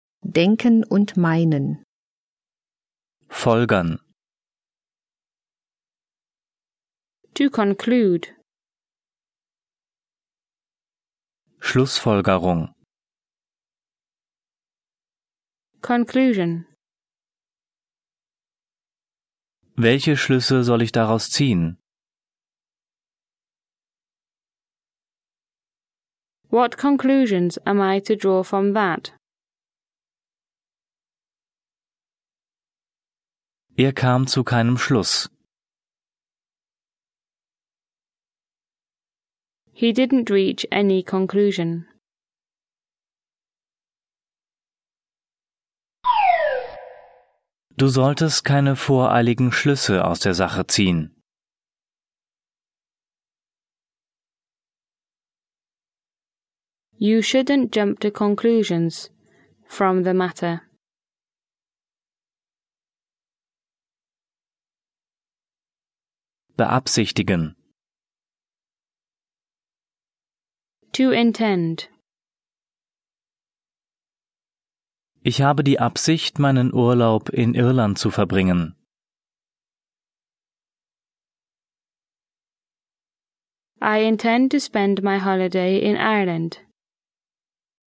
Er ist zweisprachig aufgebaut (Deutsch - Englisch), nach Themen geordnet und von Muttersprachlern gesprochen. Übersetzungs- und Nachsprechpausen sorgen für die Selbstkontrolle.